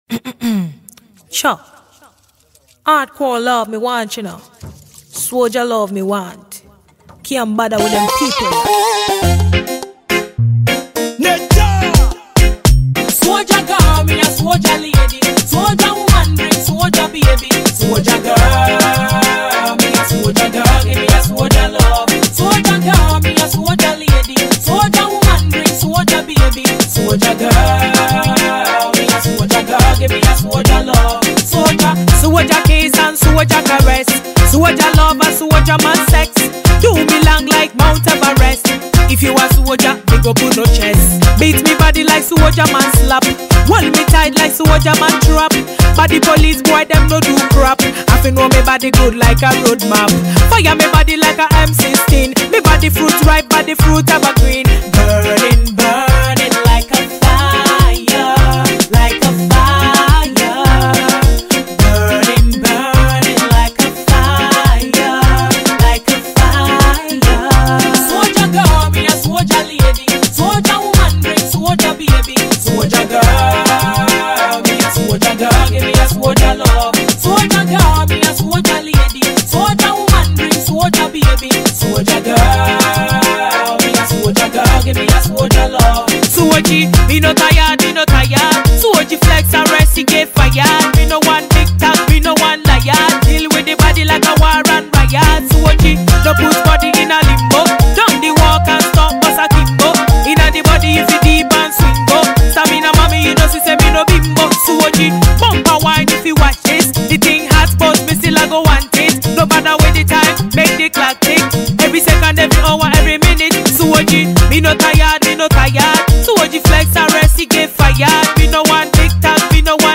Ghanaian female Dancehall artist